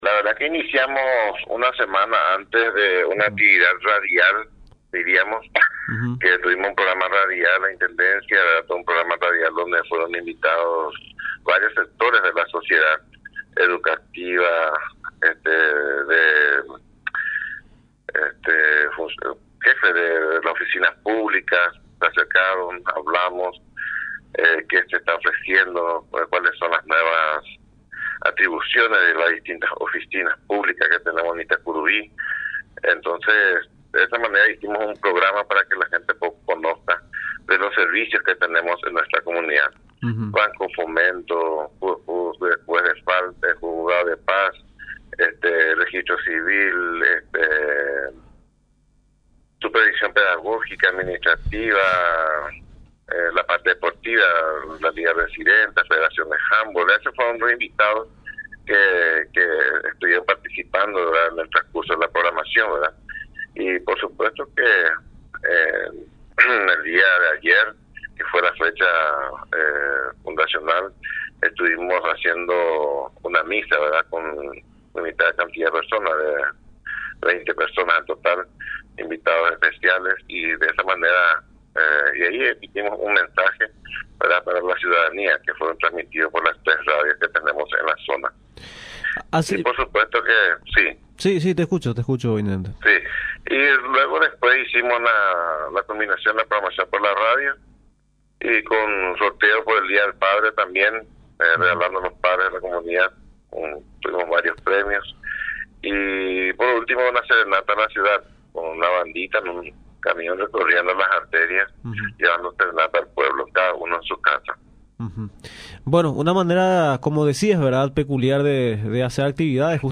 Manifestó, en declaraciones a Radio Nacional San Pedro, que la comunidad no cuenta con un producto estrella que lo caracteriza, pero que se destacan con la producción de rubros hortícolas y de la piscicultura.
Intendente-de-Itacurubi-Alberto-Melo.mp3